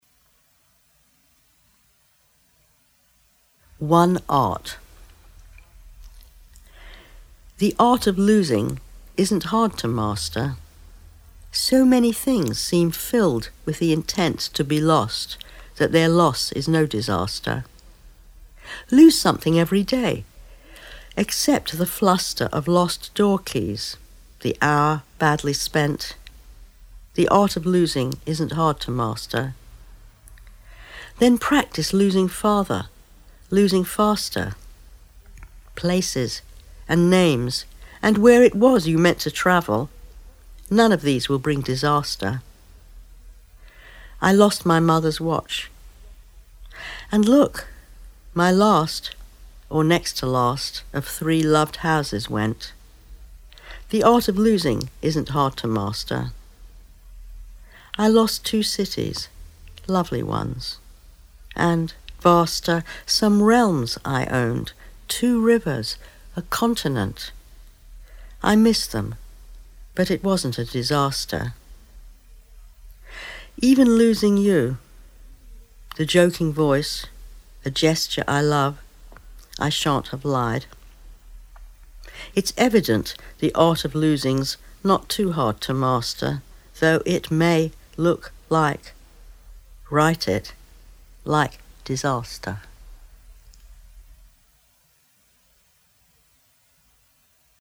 The Poet Speaks from Poems on the Underground Audiobook 1994
One Art by Elizabeth Bishop read by Ruth Fainlight